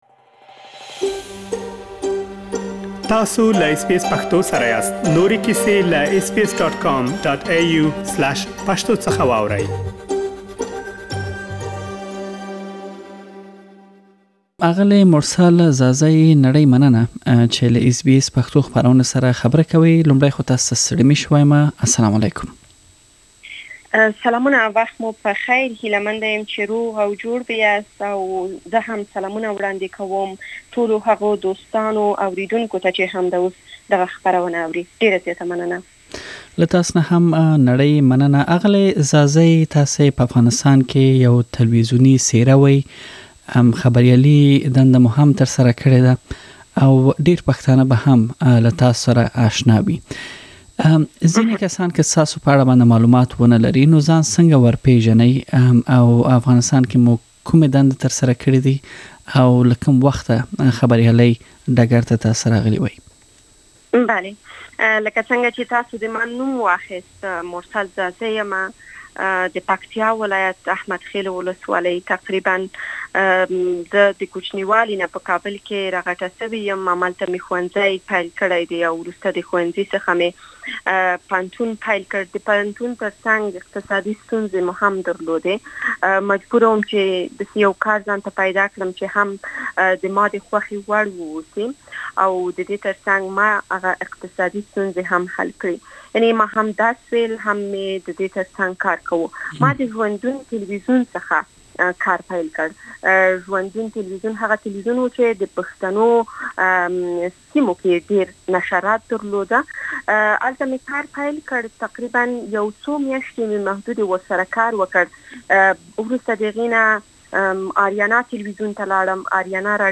ځانګړې مرکه